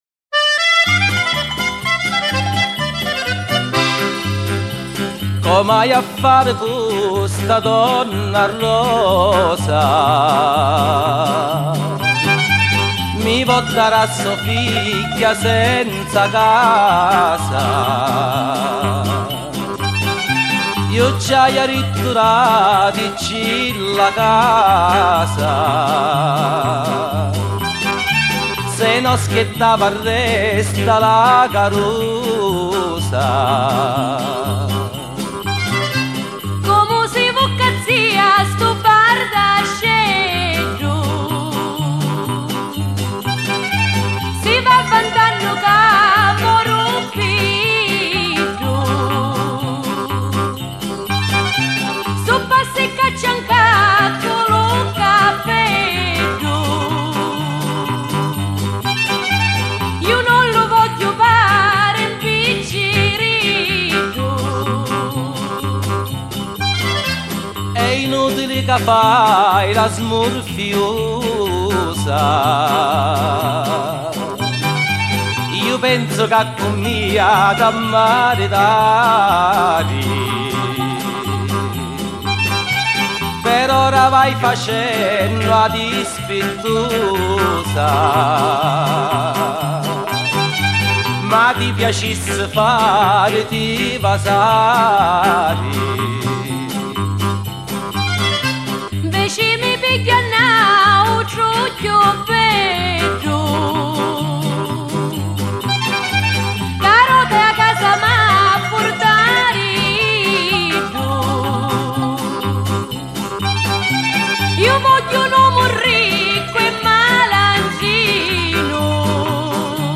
Canti-popolari-italiani-Sicilia-A-figghia-i-donna-rosa.mp3